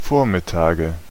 Ääntäminen
Ääntäminen Tuntematon aksentti: IPA: /ˈfoːɐ̯mɪˌtaːɡə/ Haettu sana löytyi näillä lähdekielillä: saksa Käännöksiä ei löytynyt valitulle kohdekielelle. Vormittage on sanan Vormittag monikko.